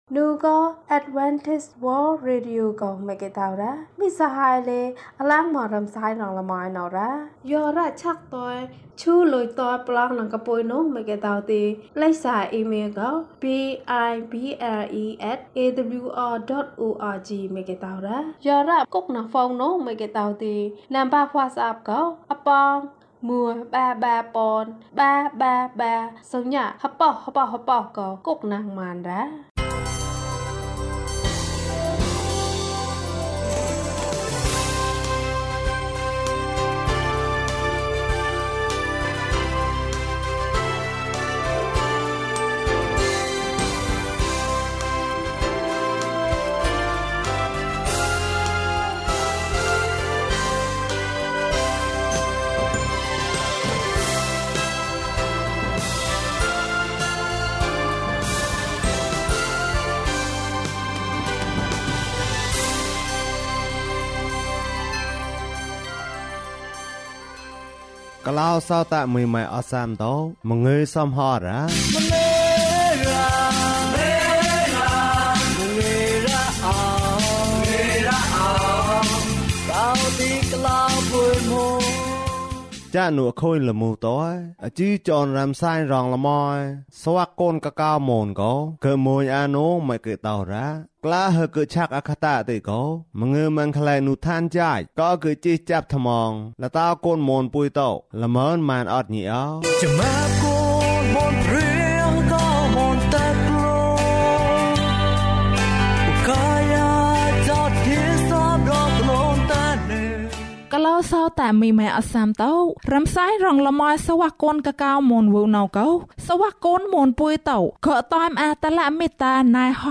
ကမ်းခြေမှာ လမ်းလျှောက်တယ်။ ကျန်းမာခြင်းအကြောင်းအရာ။ ဓမ္မသီချင်း။ တရားဒေသနာ။